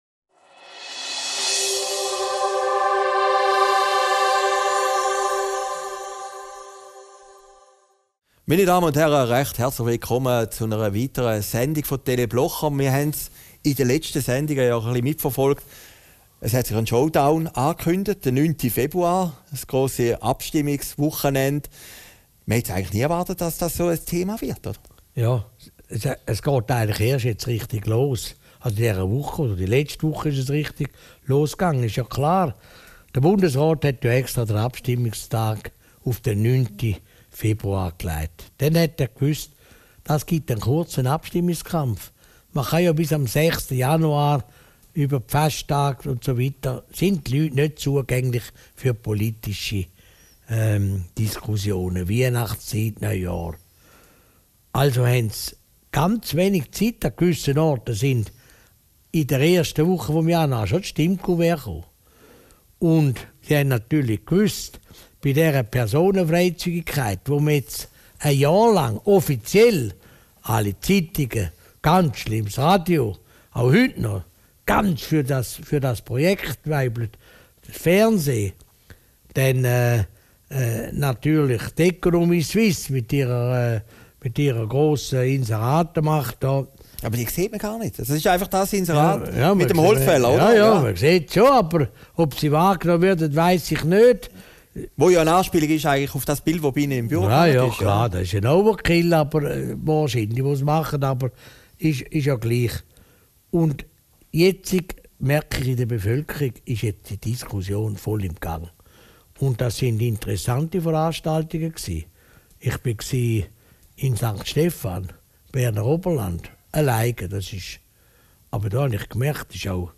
Video downloaden MP3 downloaden Christoph Blocher über die Strategien seiner Gegner und die amourösen Eskapaden von Präsident Hollande Aufgezeichnet in Herrliberg, 28.